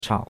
chao3.mp3